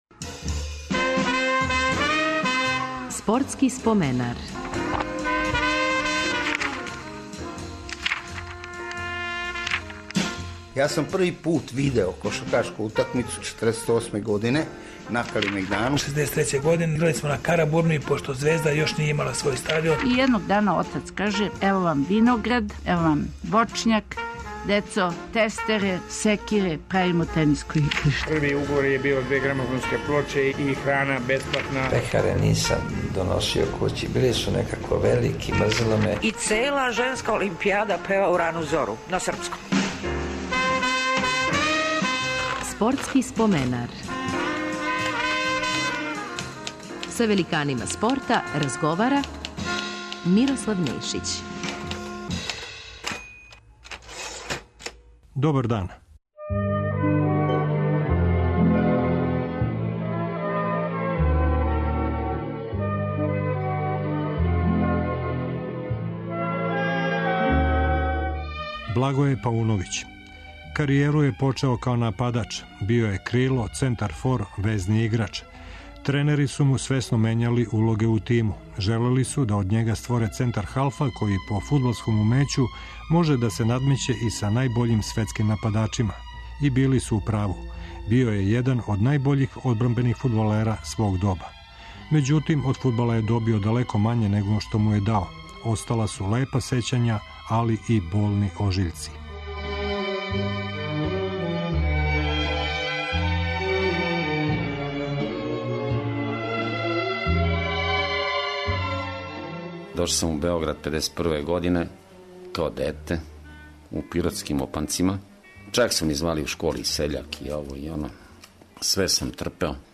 Гост ће нам бити фудбалер Благоје Пауновић. Каријеру је почео у млађим категоријама Партизана.